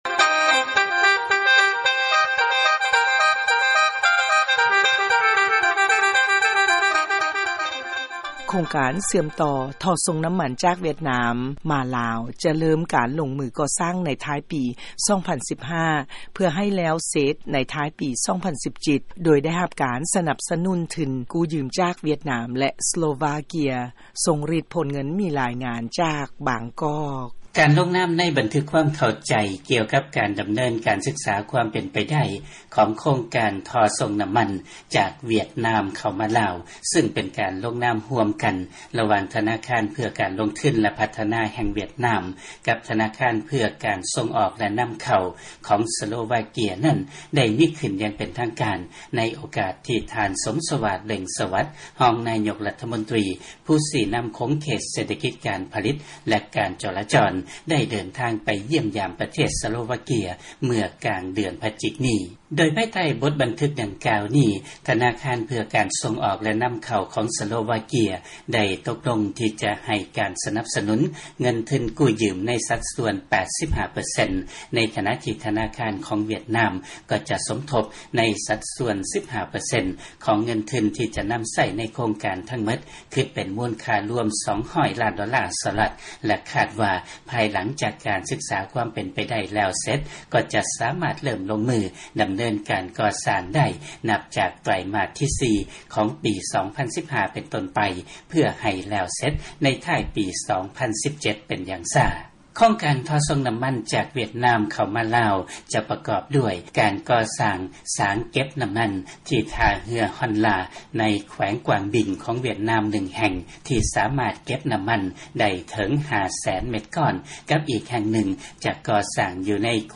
ຟັງລາຍງານ ໂຄງການເຊື່ອມຕໍ່ທໍ່ສົ່ງນ້ຳມັນ ຈາກຫວຽດນາມ ມາລາວຈະເລີ້ມລົງມືກໍ່ສ້າງໃນທ້າຍປີ 2015.